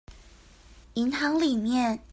Тайваньский 430